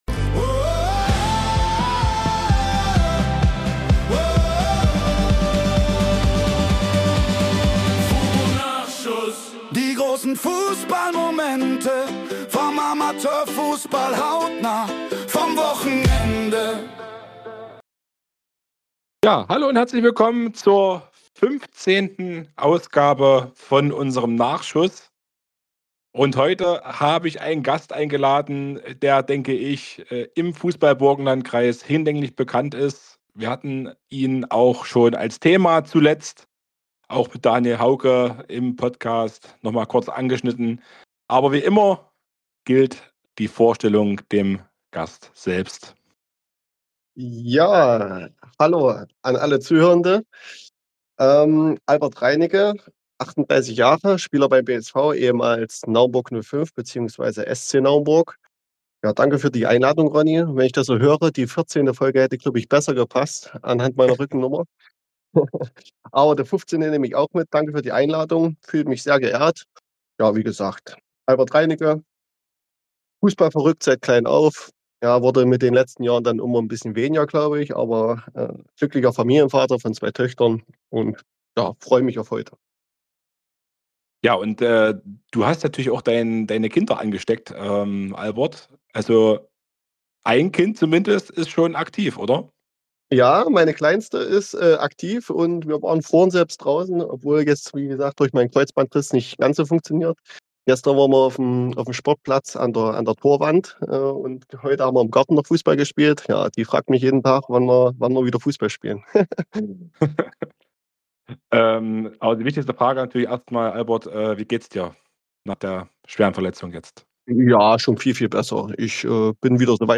Nachschuss - der Fußballtalk aus dem Burgenlandkreis Podcast